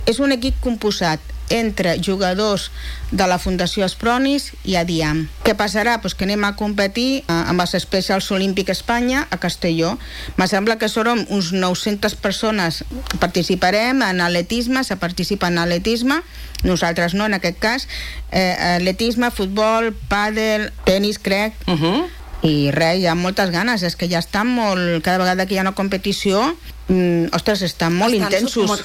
en una entrevista al matinal A L’FM i+.